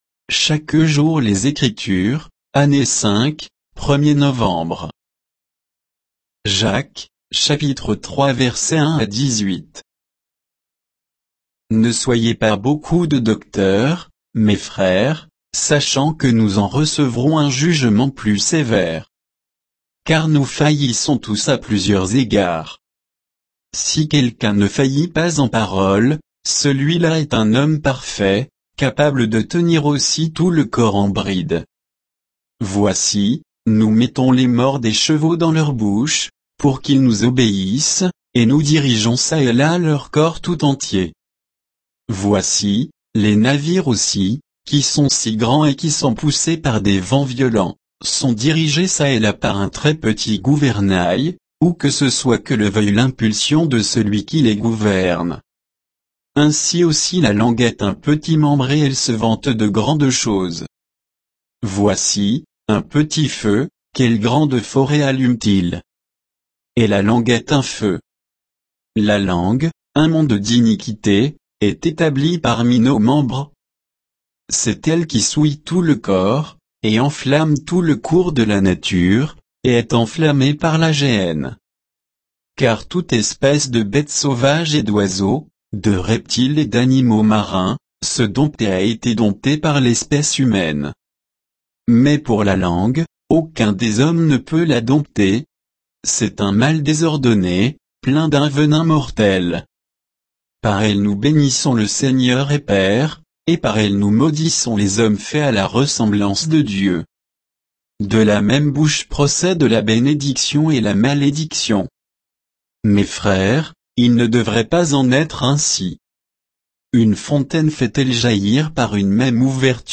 Méditation quoditienne de Chaque jour les Écritures sur Jacques 3, 1 à 18